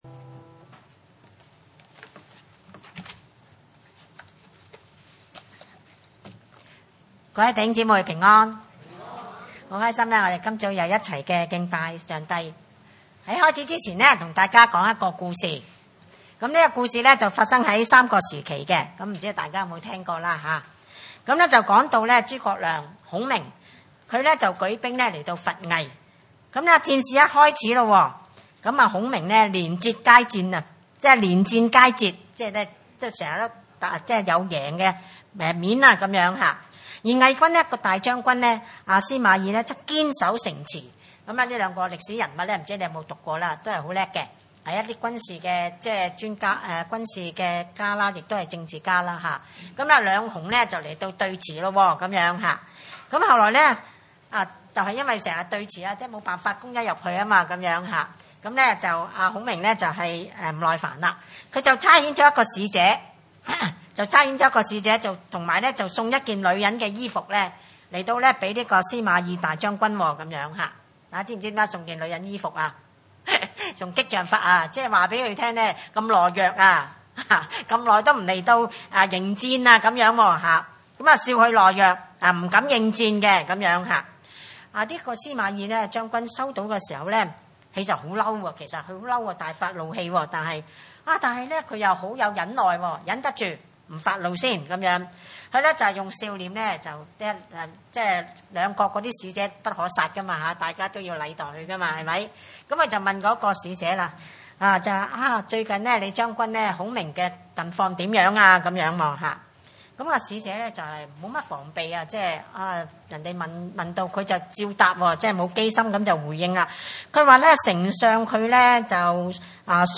崇拜類別: 主日午堂崇拜